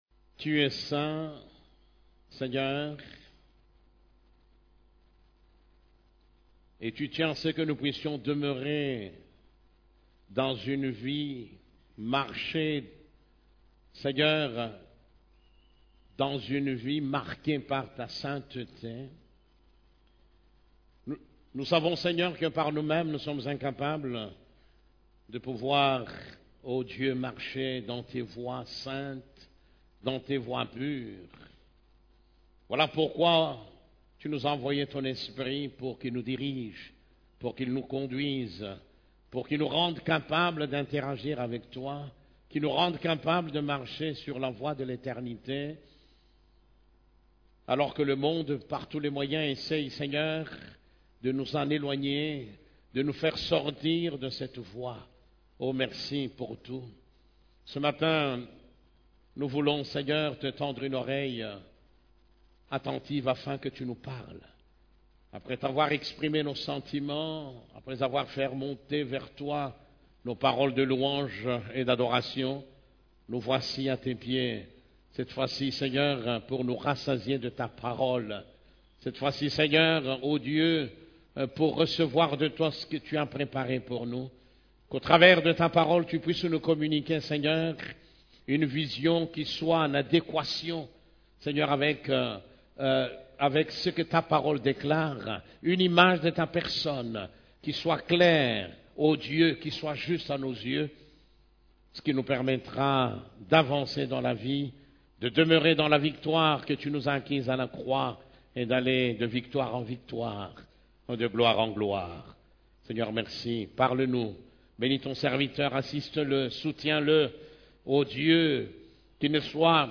CEF la Borne, Culte du Dimanche, Le Seigneur est proche de ses enfants